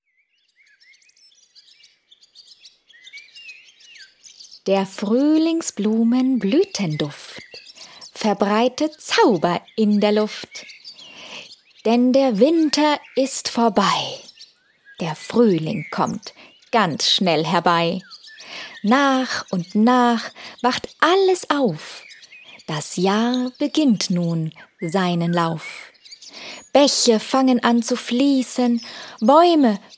Tanz- und Bewegungslieder für Kinder
Witzige Bewegungslieder für Kinder unter drei Jahren
Zum Hören, Mitsingen, Tanzen und Schieflachen.